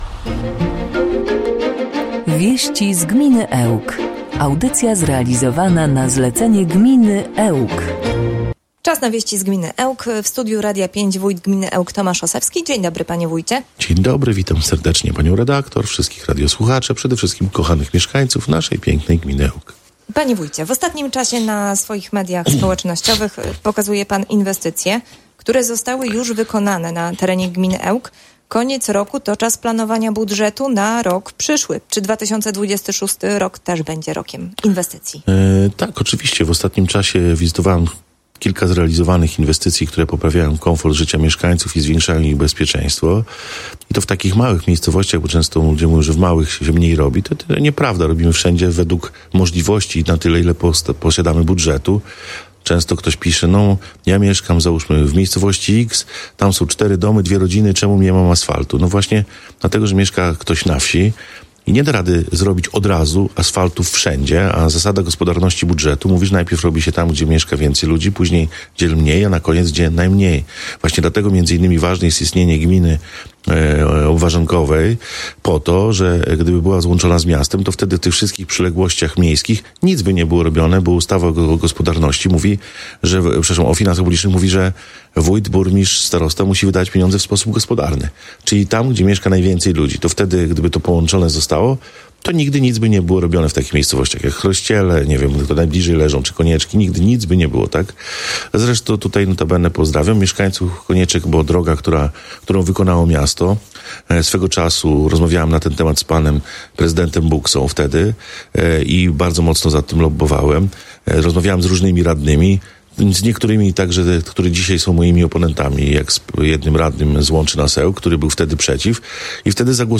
Modernizacje lokalnych dróg, nowe przedsięwzięcia i tereny inwestycyjne – to główne tematy kolejnej audycji „Wieści z gminy Ełk”. Gościem Radia 5 był Tomasz Osewski, wójt gminy Ełk.